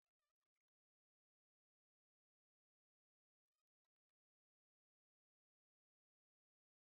Ngakak laugh annoying